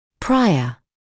[‘praɪə][‘прайэ]прежний; предшествующий